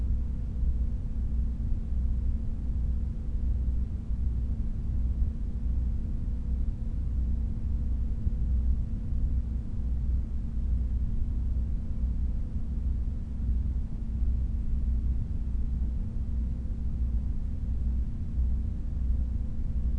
"Hum" sound files
hum1.wav